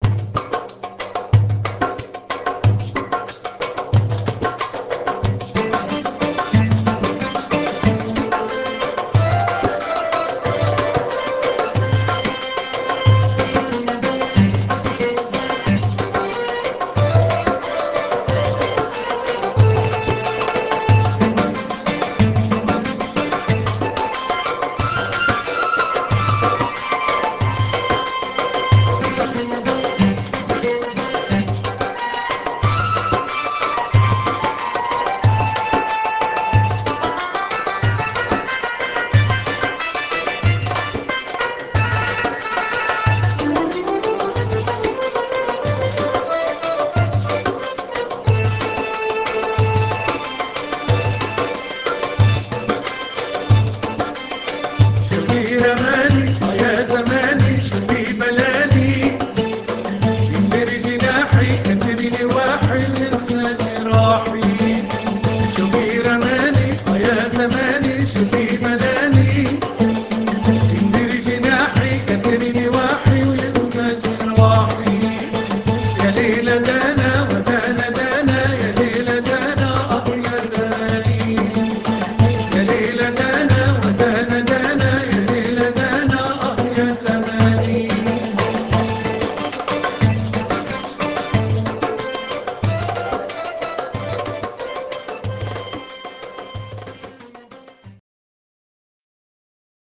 Vocals, Oud, Accordion & Keyboard
Percussion
Tabla
Nay